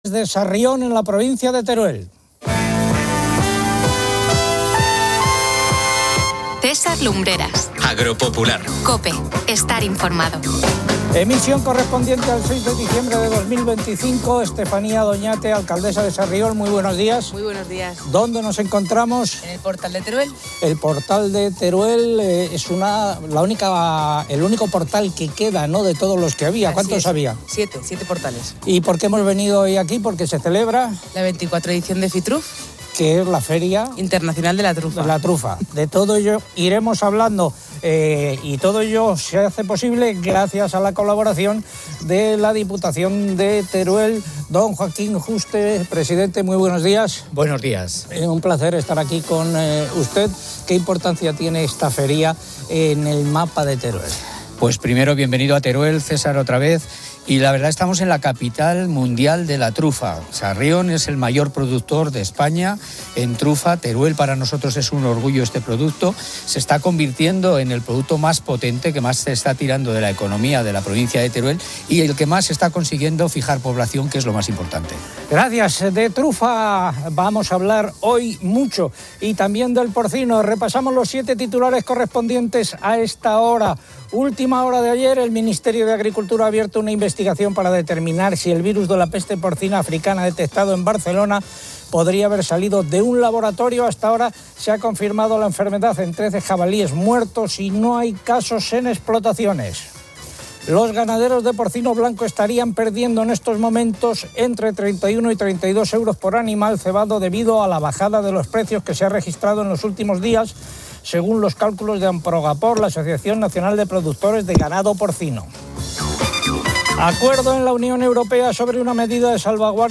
El programa se emite desde Sarrión, Teruel, con motivo de la 24ª edición de Citrus, la Feria Internacional de la Trufa, un evento clave para la...